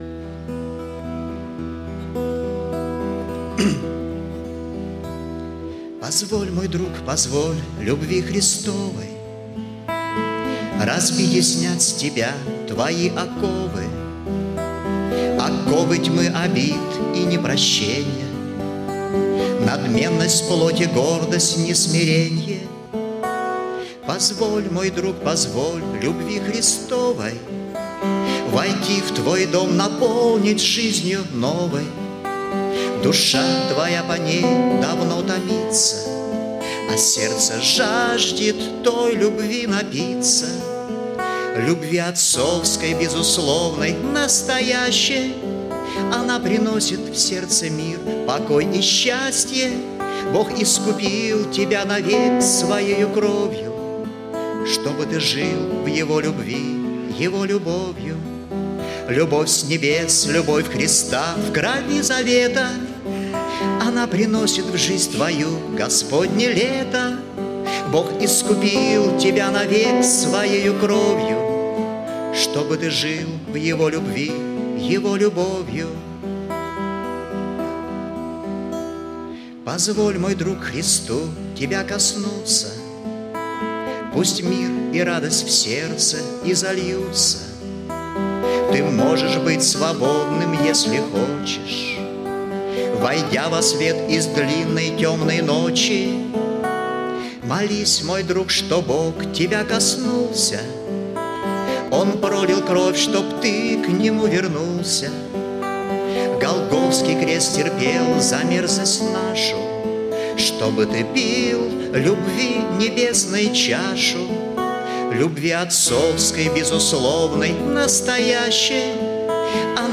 Богослужение 25.08.2019
Пение